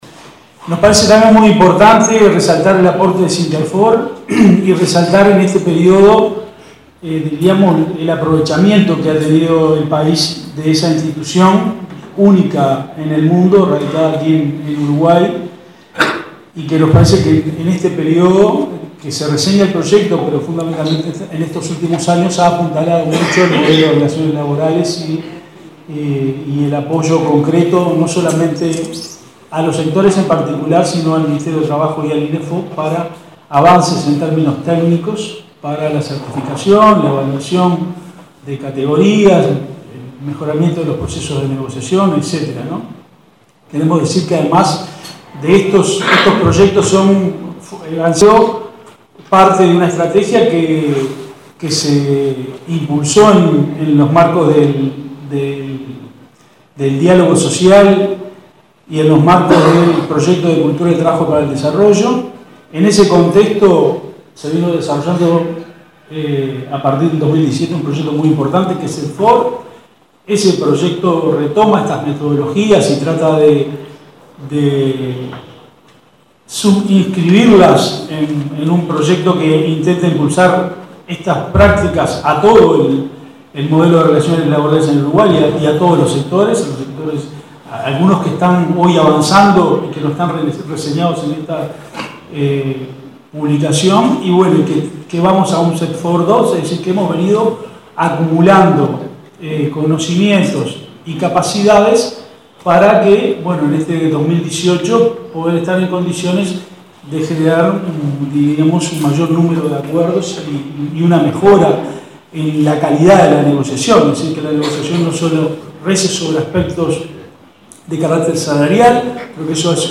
“Frente al modelo de competitividad a la baja, basado en reducción de salarios y en empleos de mala calidad, Uruguay tiene una estrategia de desarrollo que mira aspectos económicos, sociales y humanos”, afirmó el director de Empleo, Eduardo Pereyra, en la presentación de la publicación “Desarrollo de competencias sectoriales y dialogo social, la experiencia del Uruguay”, que se realizó este jueves en el Ministerio de Trabajo.